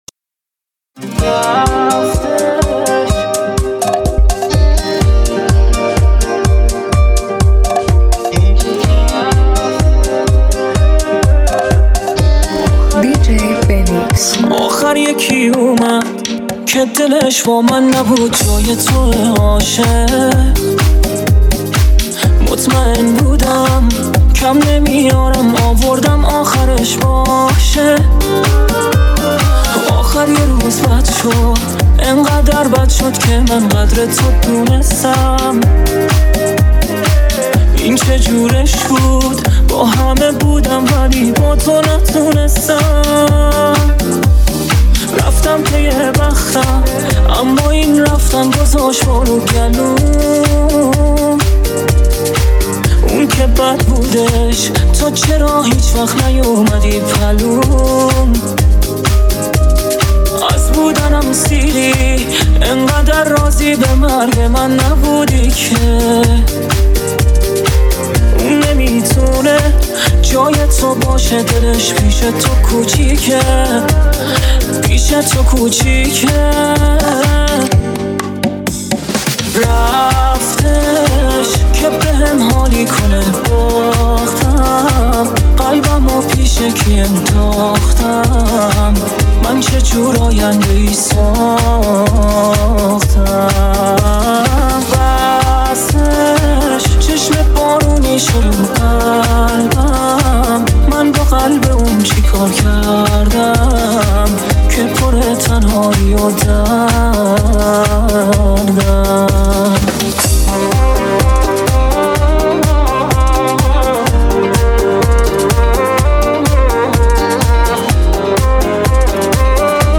دانلود ریمیکس شاد و پرانرژی